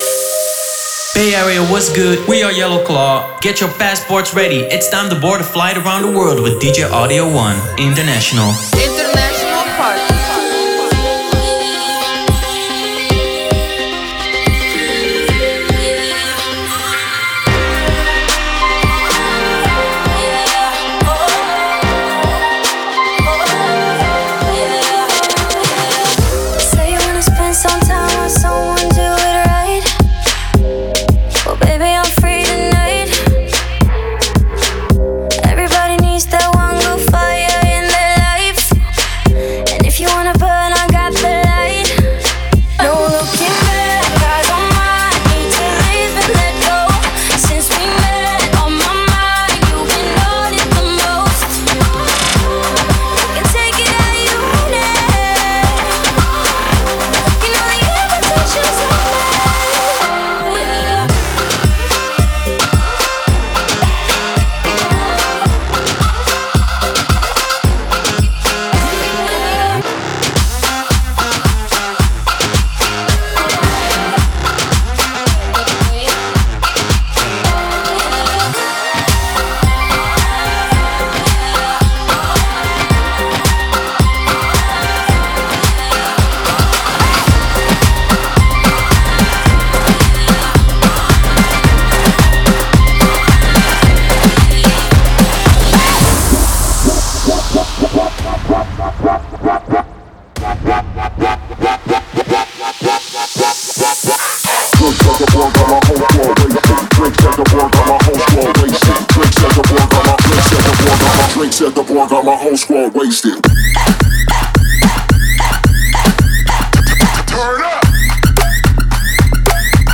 DJ
fast paced mixing and turntable skills.
hip-hop, dancehall, twerk and 100bpm bass cuts